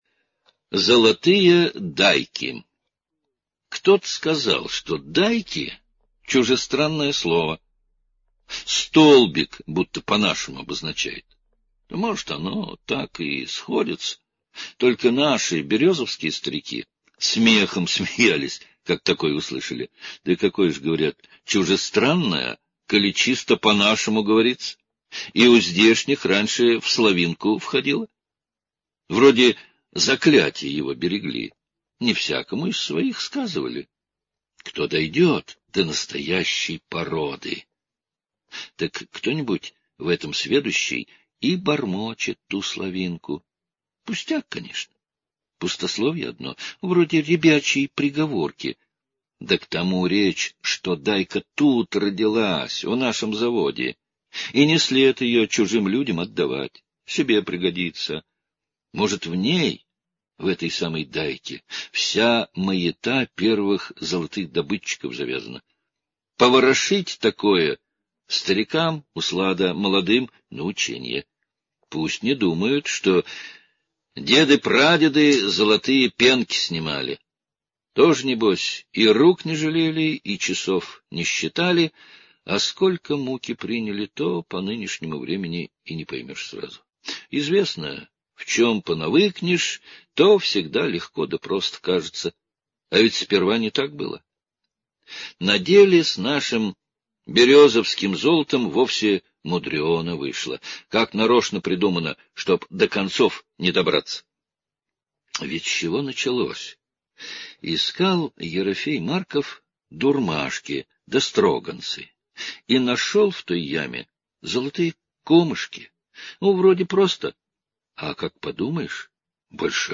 Золотые дайки - аудиосказка Павла Бажова - слушать онлайн